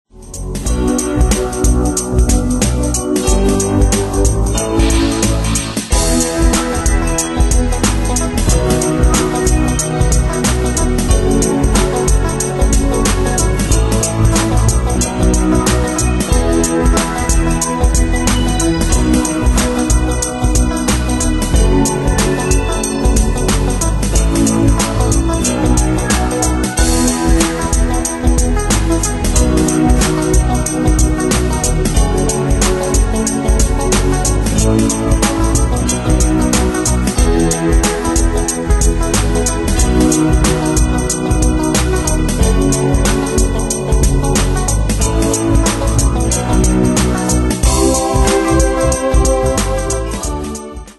Style: PopAnglo Année/Year: 1996 Tempo: 92 Durée/Time: 4.24
Danse/Dance: Ballad Cat Id.
Pro Backing Tracks